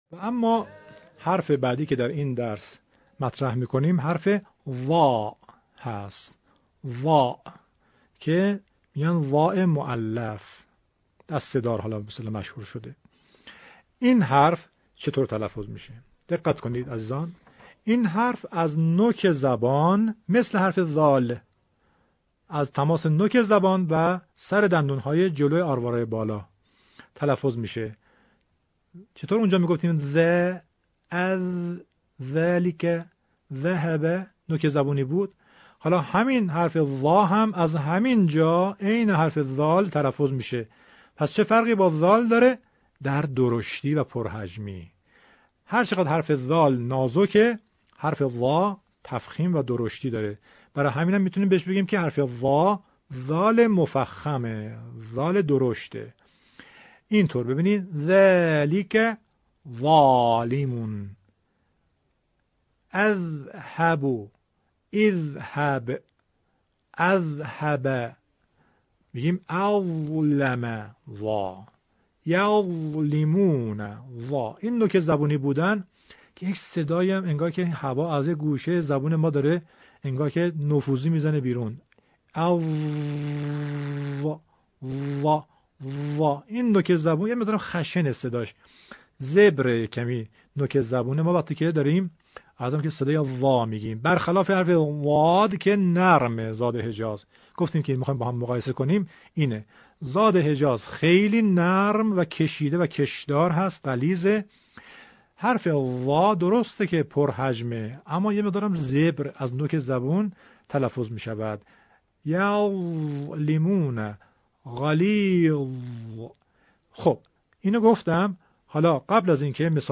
هنگام تلفظ حرف «ظ» ٬ مانند حرف «ذ» سر زبان به پشت سر دندان های پیشین بالا برخورد می کند.
۴- فاصله بین سقف و سطح دهان را زیاد کنید تا آن حرف درشت تلفظ شود.